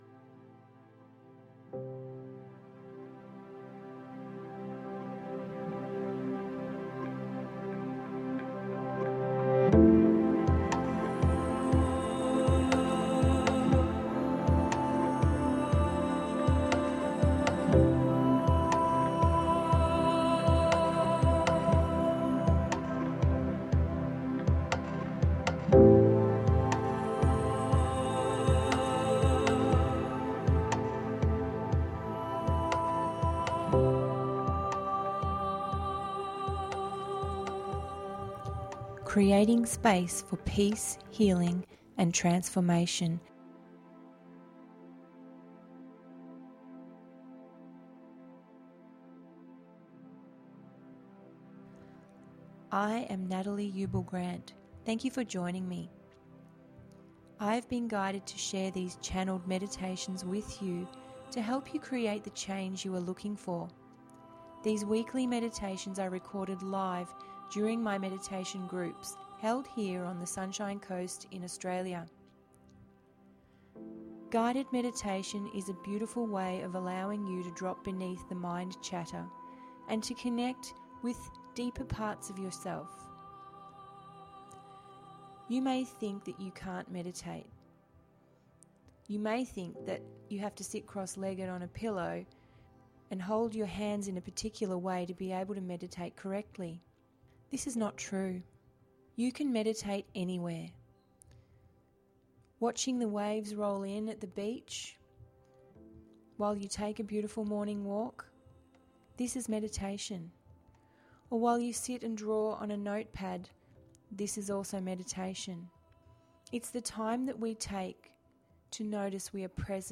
101…Quan Yin and Ra Self-insight – GUIDED MEDITATION PODCAST